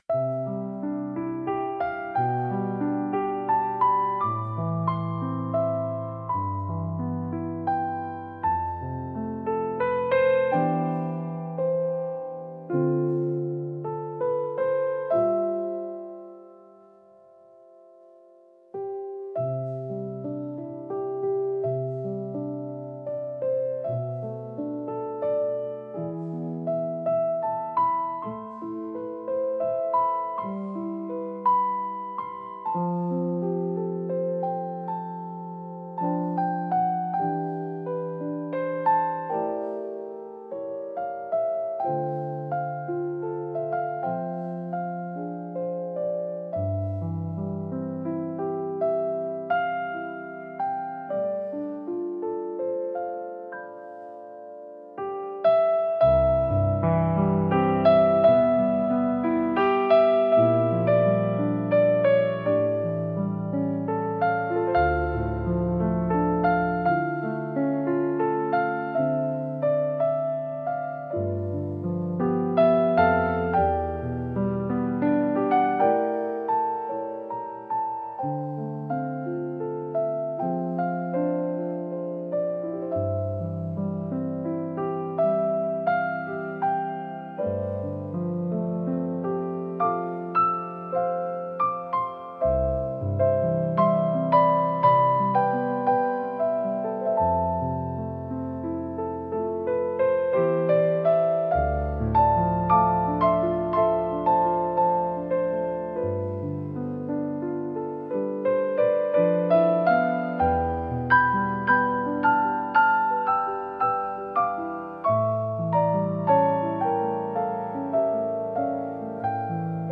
聴く人にやすらぎと温かみを提供し、心を穏やかに整える効果があります。繊細で情感豊かな空気を醸し出すジャンルです。